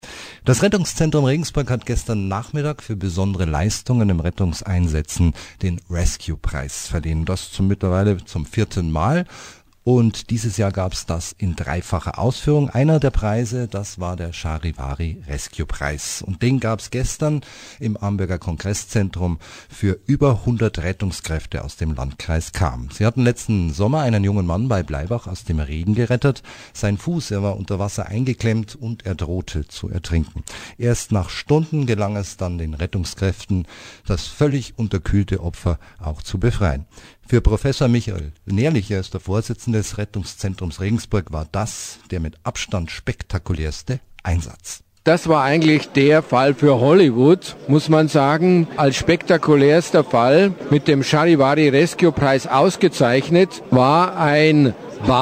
Großer Bericht zur Preisverleihung (2,4MB MP3-File)